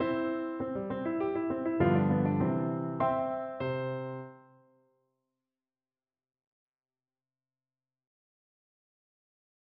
같은 악장의 종결 종지는 기본 위치의 화음을 특징으로 한다.
모차르트 피아노 소나타 C장조, K. 545, 1악장 마지막 부분 (오디오)